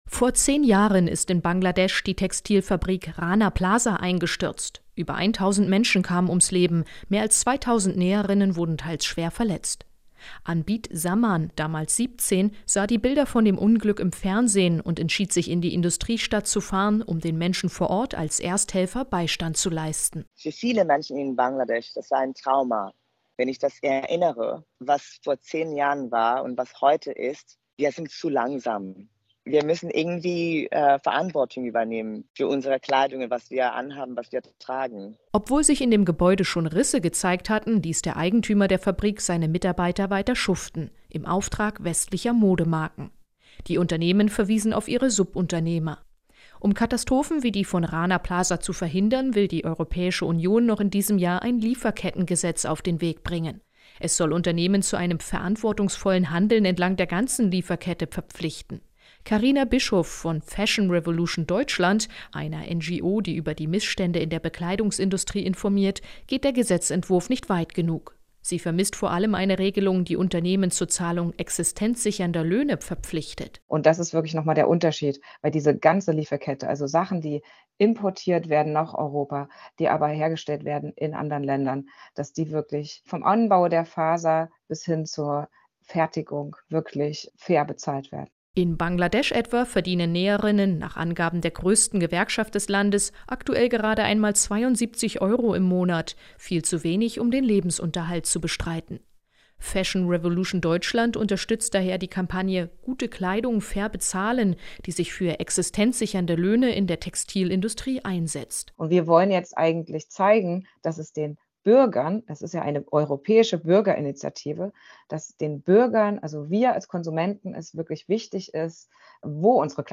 Inforadio Nachrichten, 24.08.2023, 06:00 Uhr - 24.08.2023